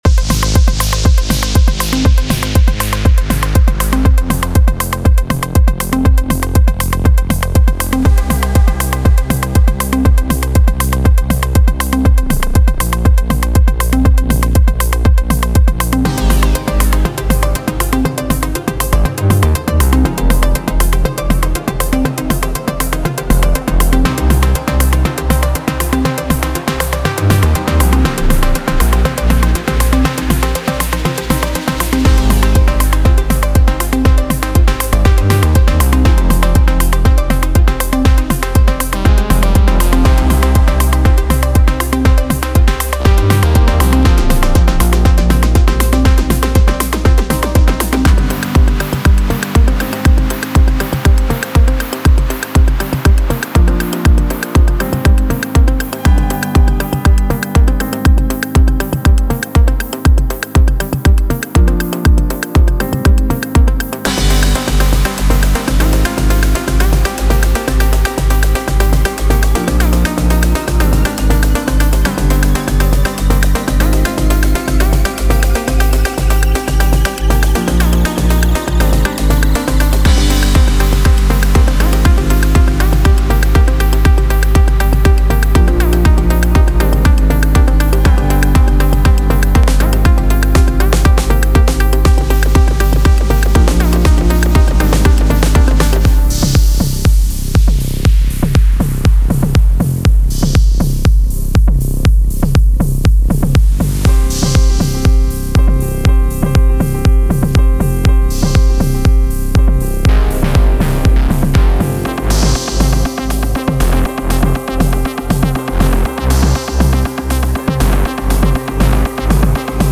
Cマイナーキーで構成された362MBのこのパックは、アフロハウスとエレクトロハウスを融合させたコレクションです。
デモサウンドはコチラ↓
Genre:Afro House